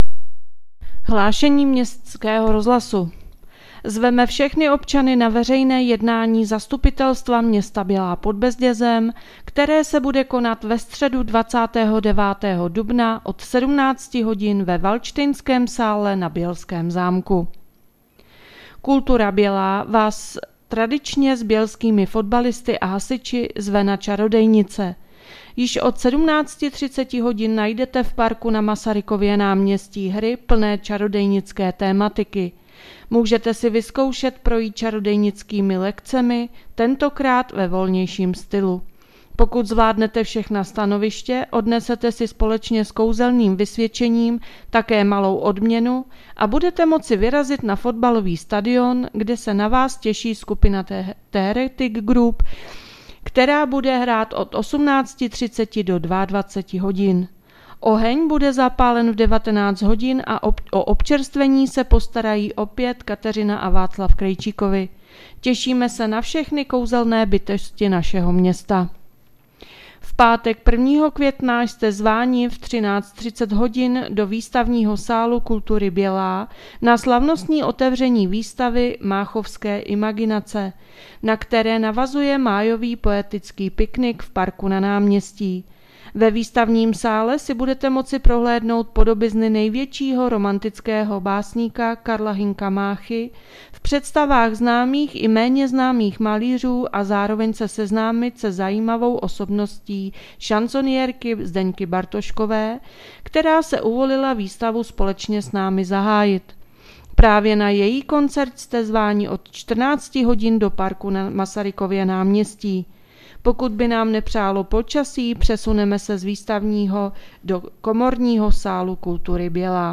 Hlášení městského rozhlasu 27.4.2026